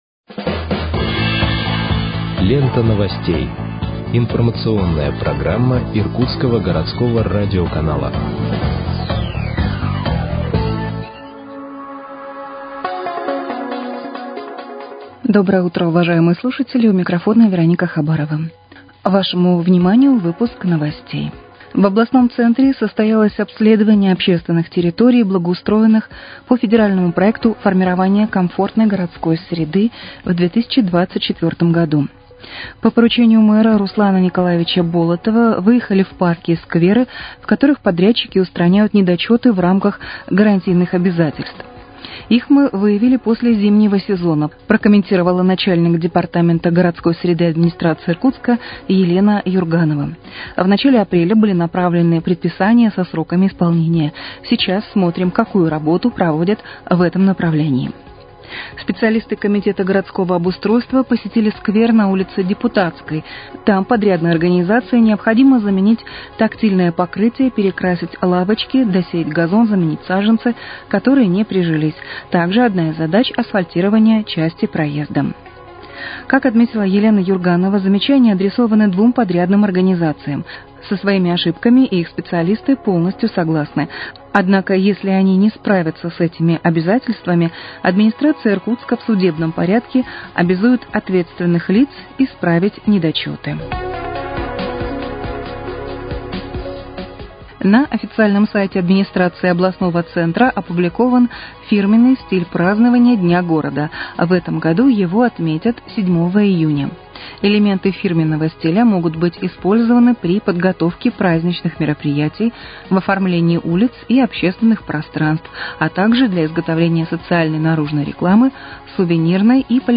Выпуск новостей в подкастах газеты «Иркутск» от 30.05.2025 № 1